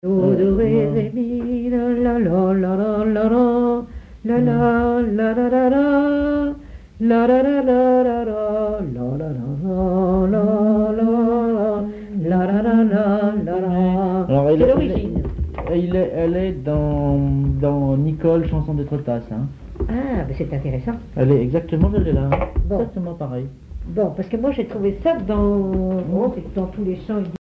Chanson chantonnée
Pièce musicale inédite